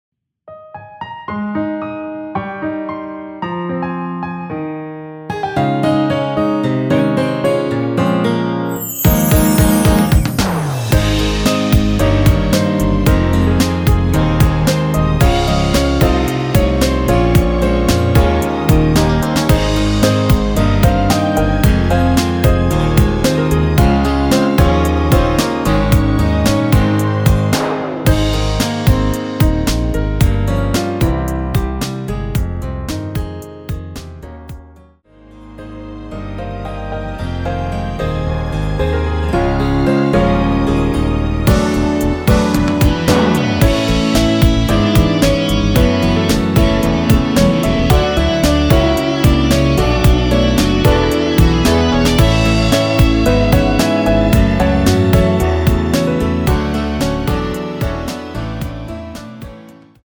원키에서(-3)내린 MR입니다.
Ab
앞부분30초, 뒷부분30초씩 편집해서 올려 드리고 있습니다.
중간에 음이 끈어지고 다시 나오는 이유는